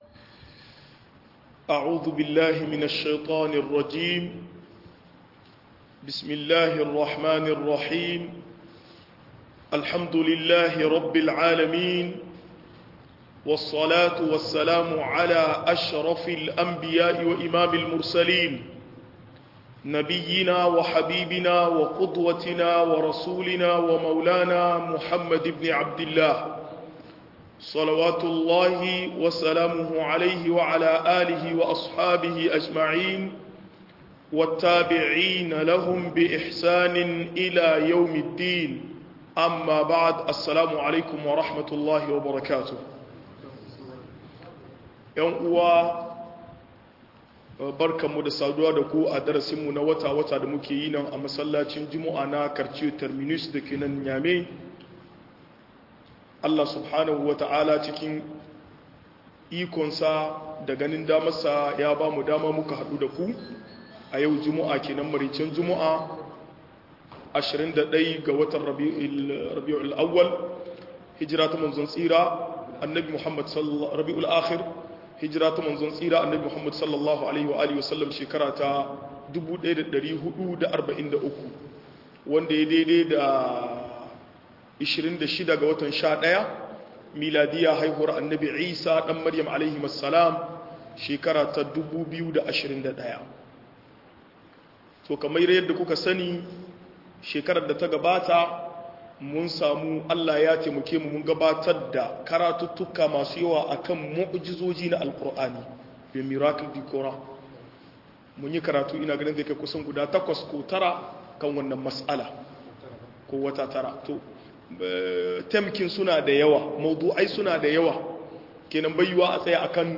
Jahilci yana bata tarbiyya - MUHADARA